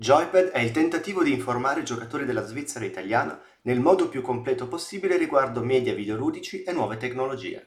Qua sotto trovate un comparativo registrato con tre microfoni: il microfono integrato della webcam Logitech Brio 4K, il microfono delle cuffie LogitechG Pro X e Razer Seiren.